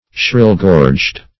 Search Result for " shrill-gorged" : The Collaborative International Dictionary of English v.0.48: Shrill-gorged \Shrill"-gorged`\, a. Having a throat which produces a shrill note.